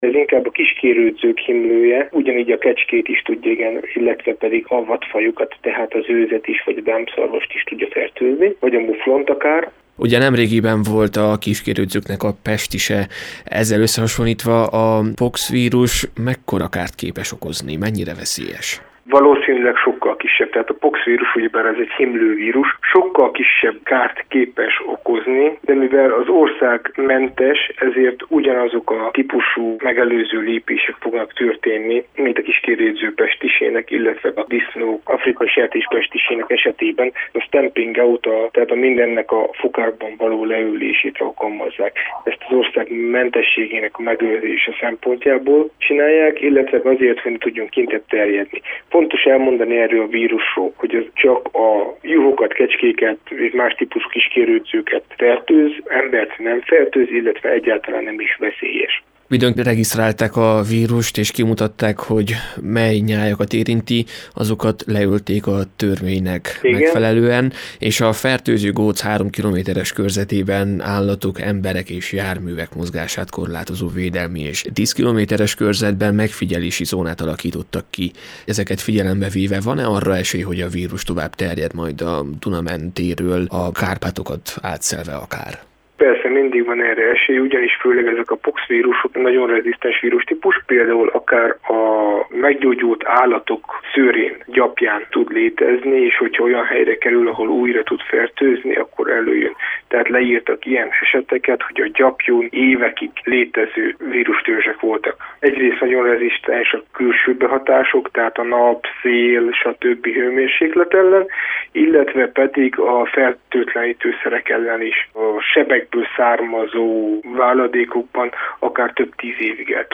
Egyelőre egy déli megyében mutatták ki - állatorvost kérdeztünk, hogy mire kell figyelni.